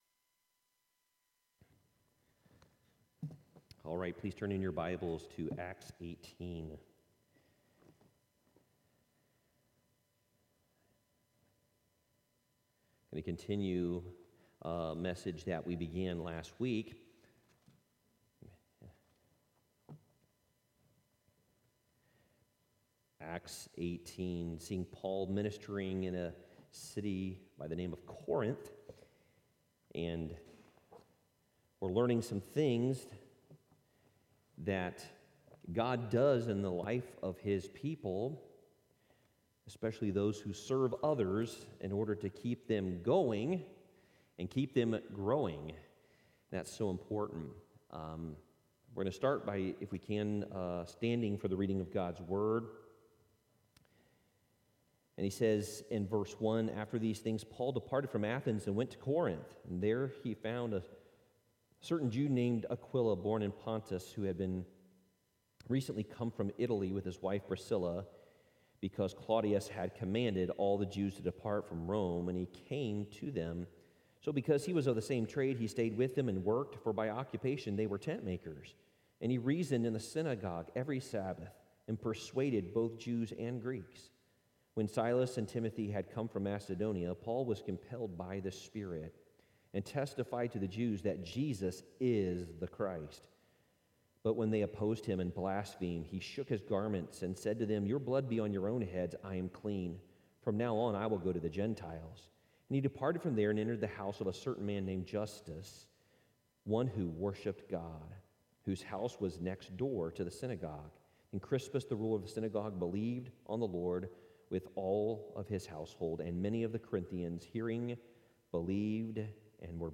Worship Service 10/24/2021